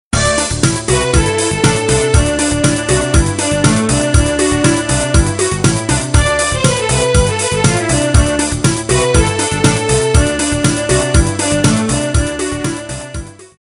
Заказ полифонической версии:
• Пример мелодии содержит искажения (писк).